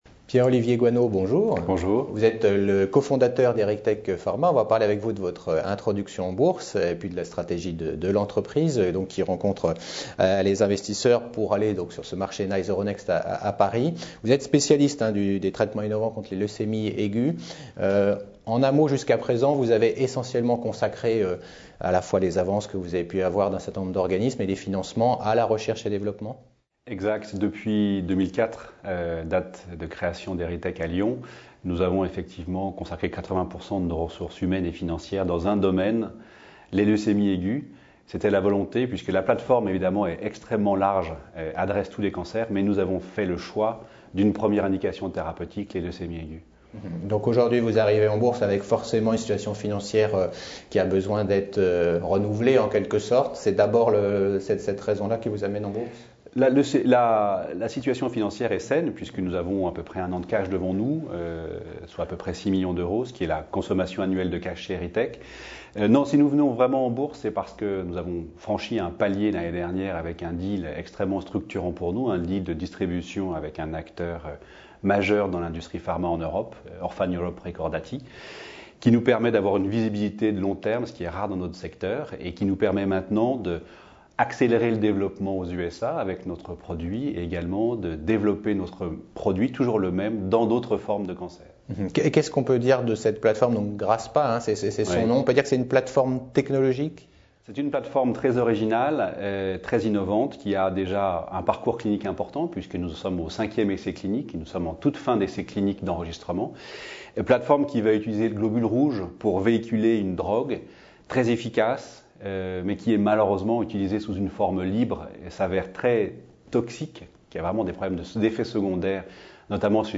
Au sommaire de l’interview :